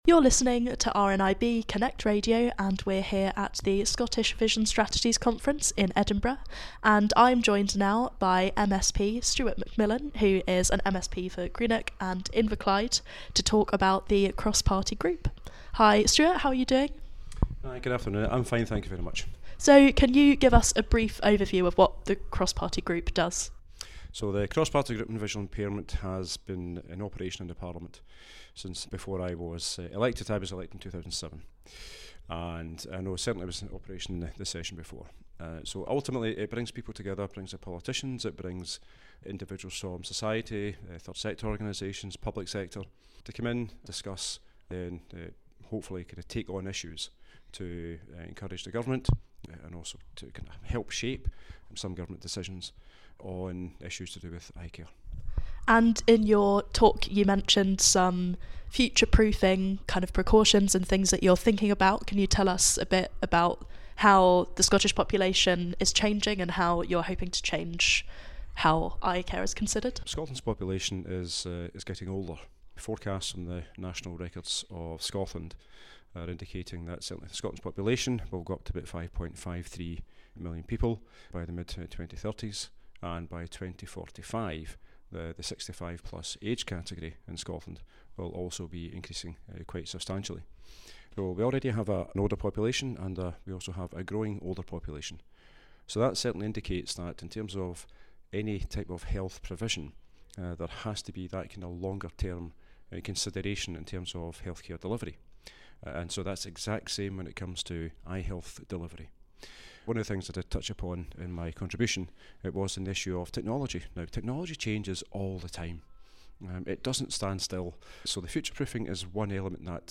Scottish Vision Strategy Conference was organised by leading sight loss charities RNIB Scotland and Sight Scotland and took place in Edinburgh on the 8th of March 2023.